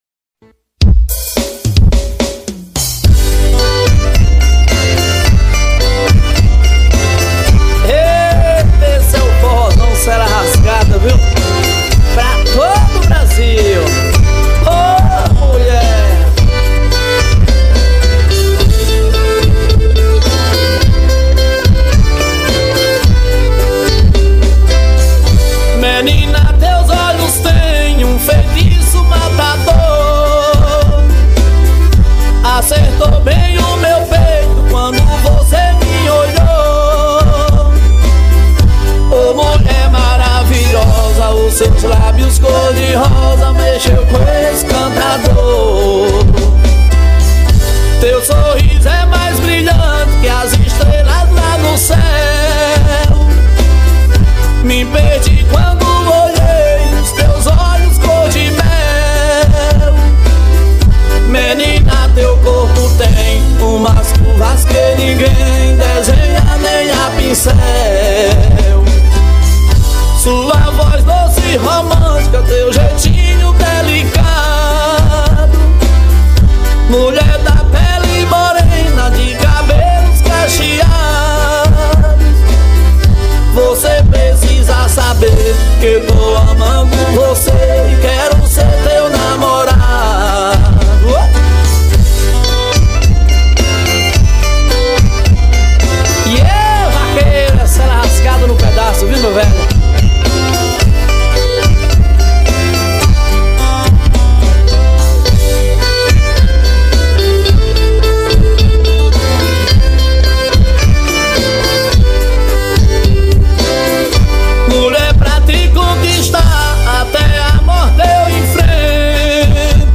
2024-02-21 06:48:49 Gênero: Forró Views